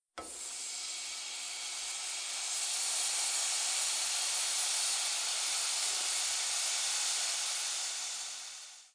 sizzle.mp3